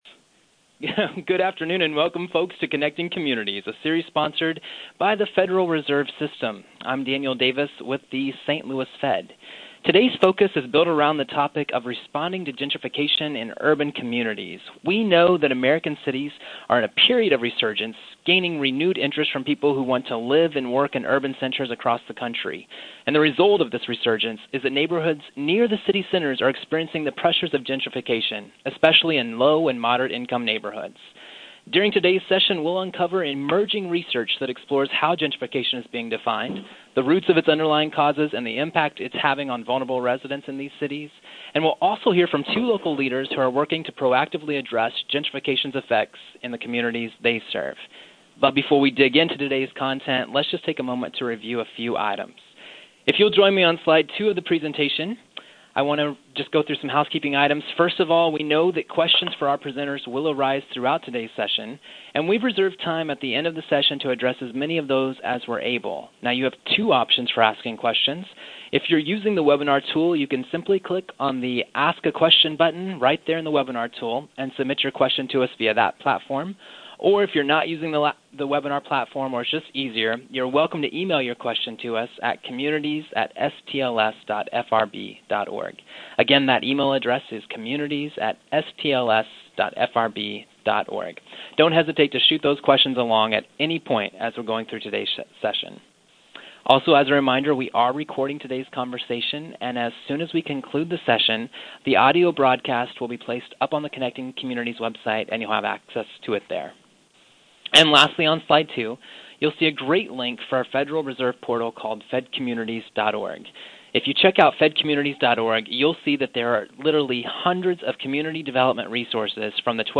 Download and listen to an audio recording of this webinar.